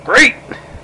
Great! Sound Effect